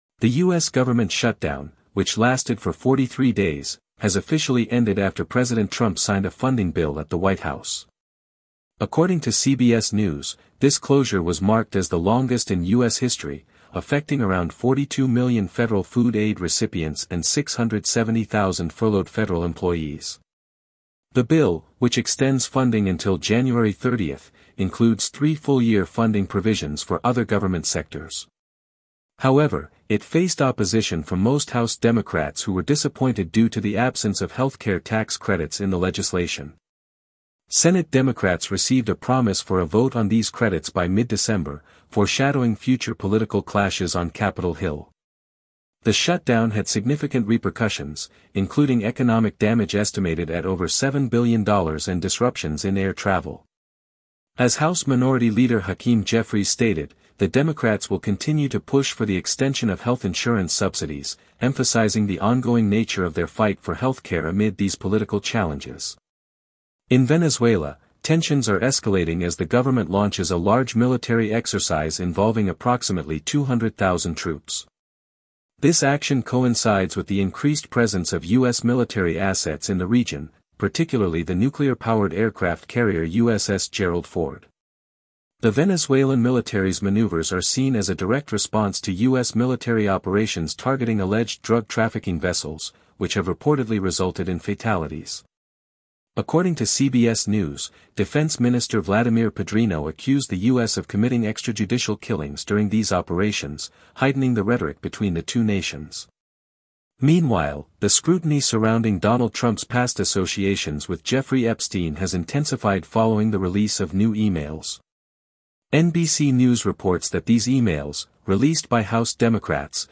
Top News Summary